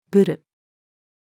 ブル-female.mp3